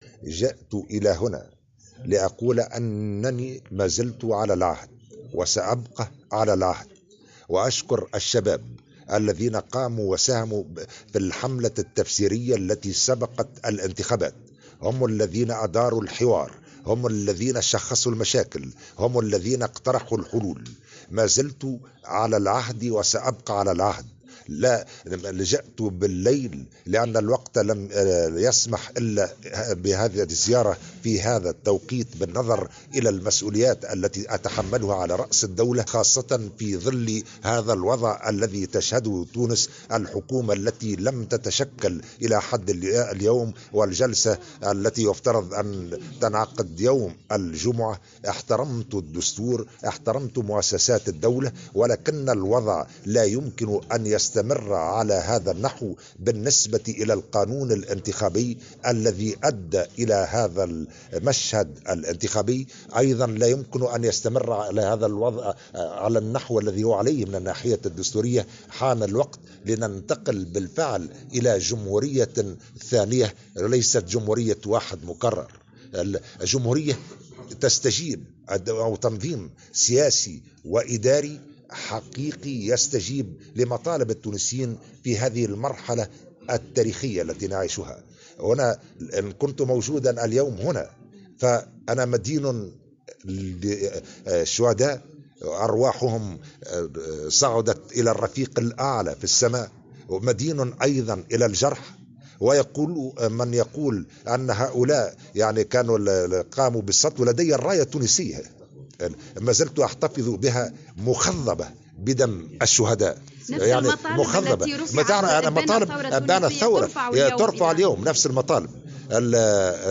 تحوّل رئيس الجمهورية قيس سعيّد مساء هذا اليوم إلى ولاية القصرين حيث أشرف على إحياء الذكرى التاسعة ليوم الشهيد.